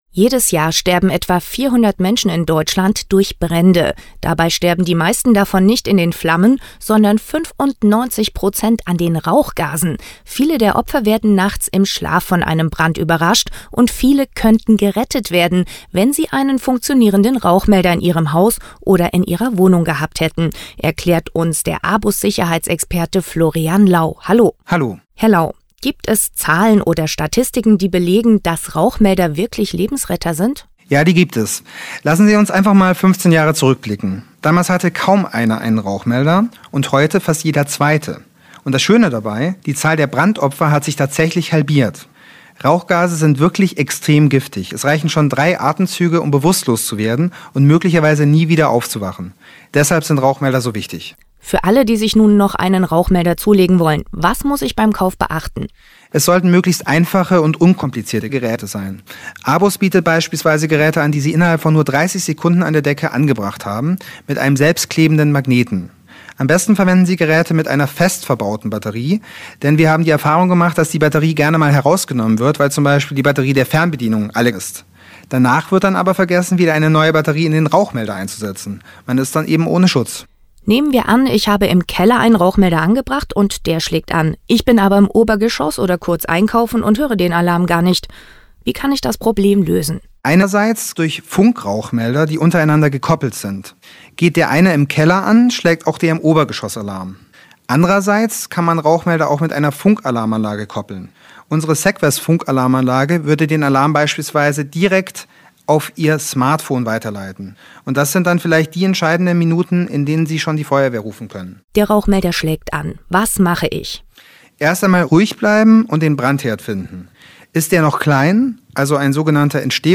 Radiobeitrag: Kleine Lebensretter – Warum Rauchmelder so wichtig sind